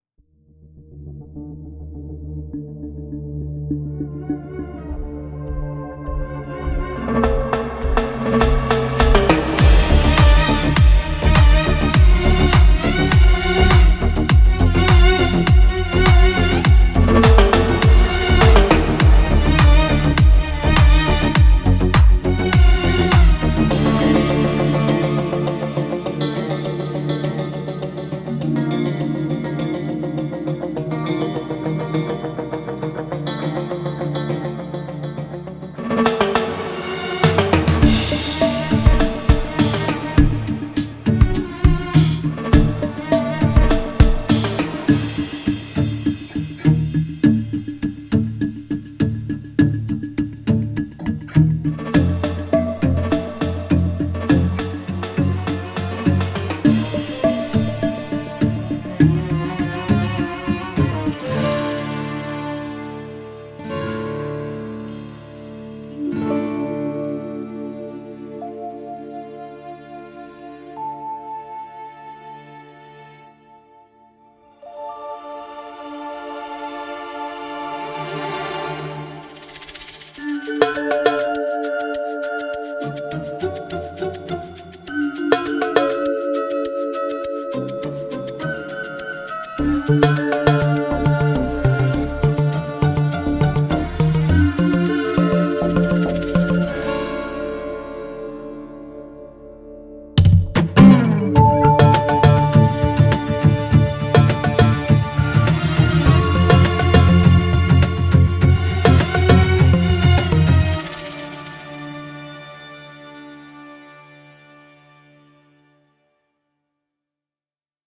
tema dizi müziği, heyecan eğlenceli enerjik fon müziği.